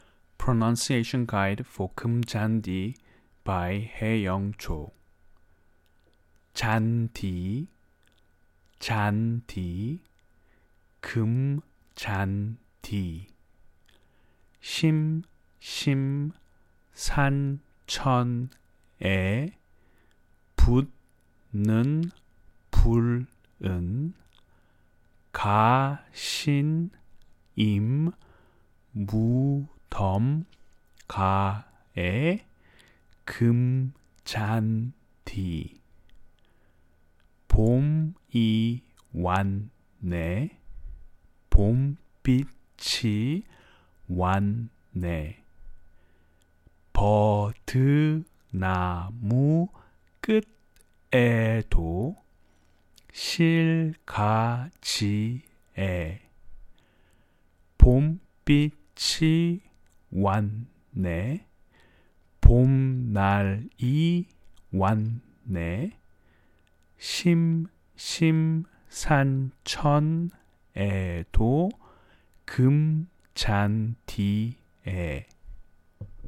Pronunciation Guide : free
SBMP1829_Golden_Field_Pronunciation_Guide.mp3